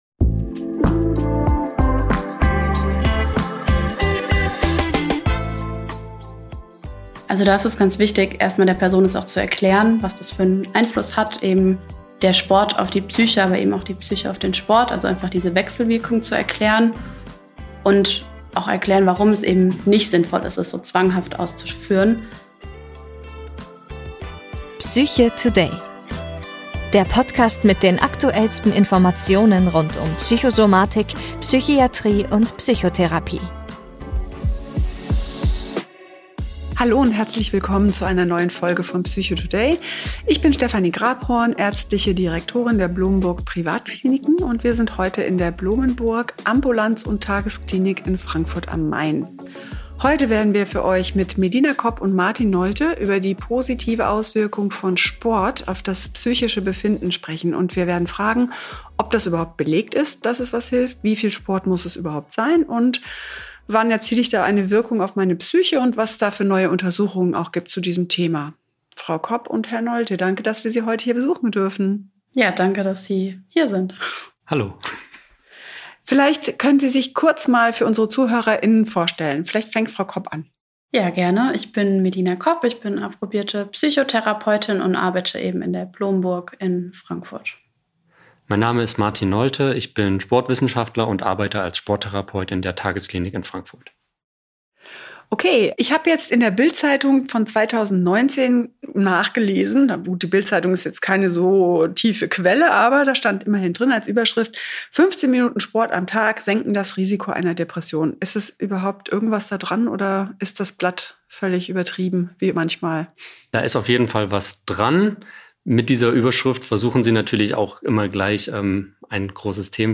unterhalten sich um die Auswirkungen von Sport auf Körper und Psyche